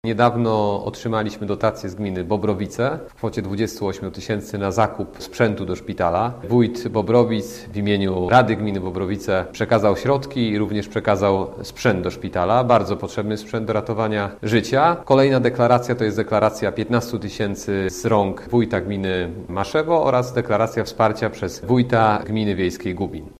Ostatnio znów otrzymaliśmy wpłaty z gmin naszego powiatu – mówi Grzegorz Garczyński, starosta krośnieński.